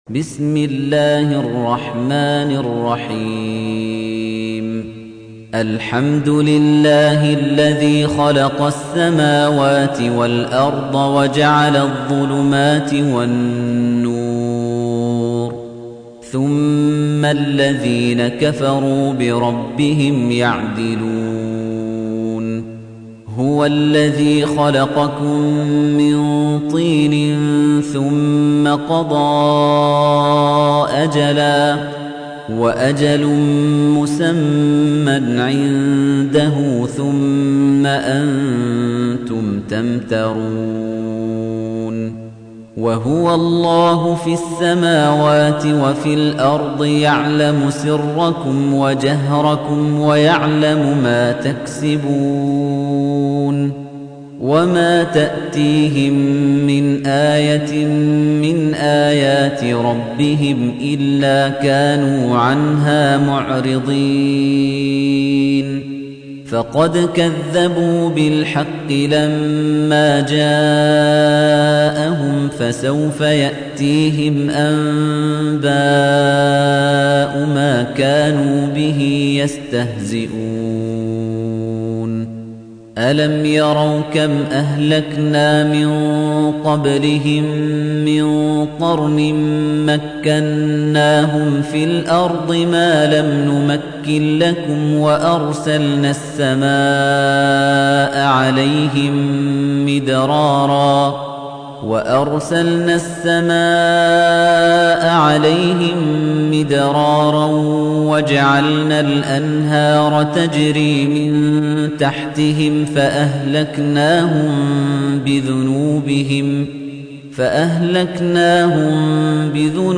تحميل : 6. سورة الأنعام / القارئ خليفة الطنيجي / القرآن الكريم / موقع يا حسين